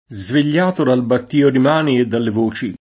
battio [ batt & o ] s. m.